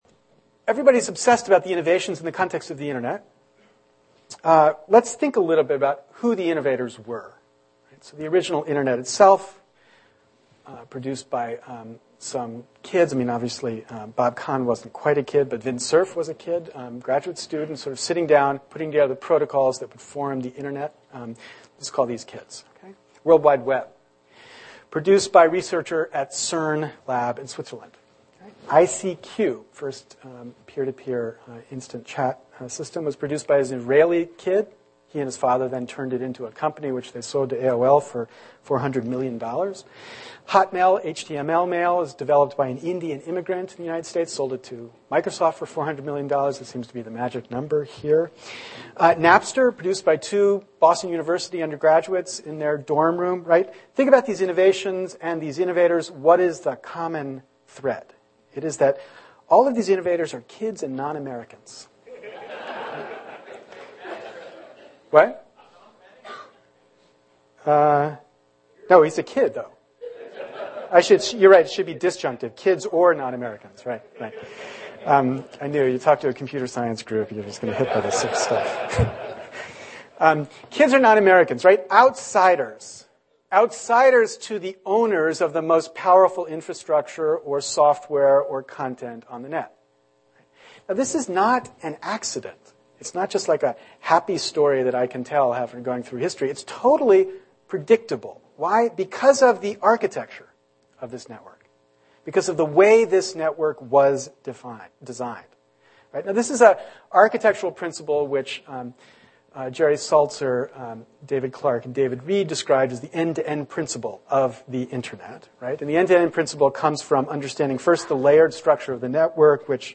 Innovation at the edge of the network Stanford University law professor Larry Lessig talks about innovation at the edge of the network (10m 35s). This is an excerpt from a talk on intellectual property .